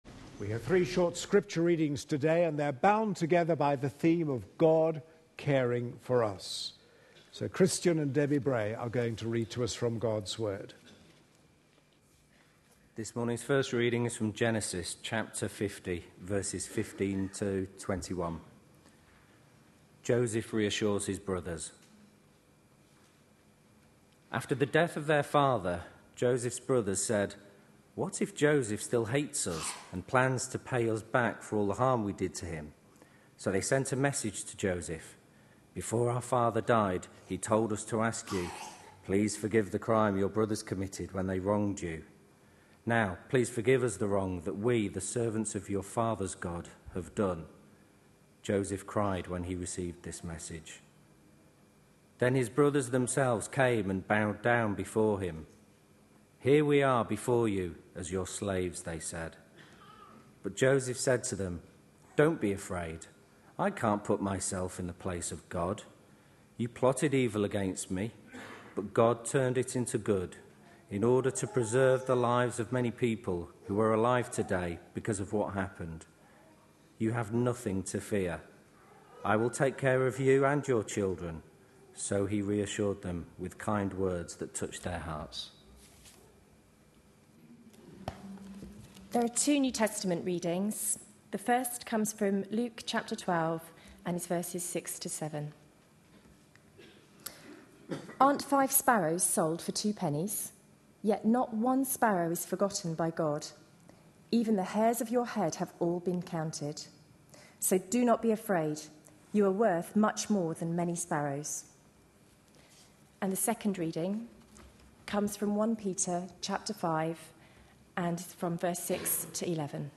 A sermon preached on 25th November, 2012, as part of our The Message of Peter for Today series.